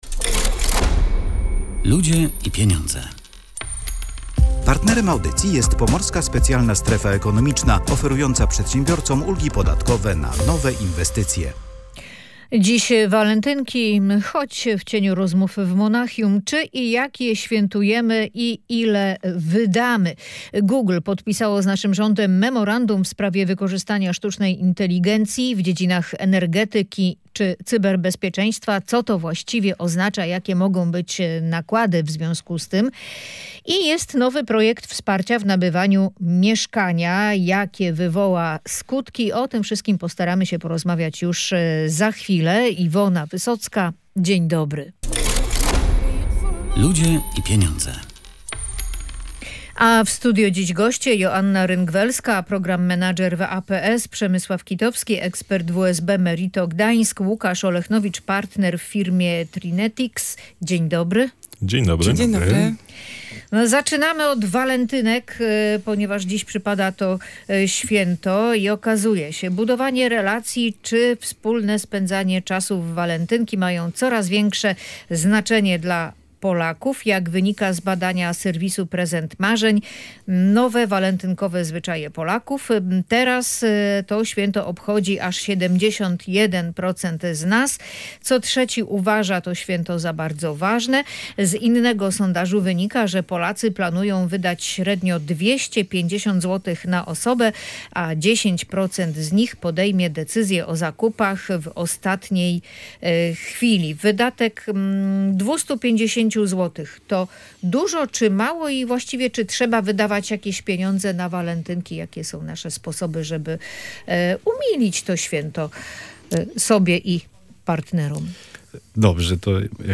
Zapowiedziana przez Google inwestycja 5 milionów dolarów w szkolenia ze sztucznej inteligencji dla Polaków jest jedynie ułamkiem kwot, jakie koncern zamierza zainwestować w naszym kraju – mówili goście audycji „Ludzie i Pieniądze”.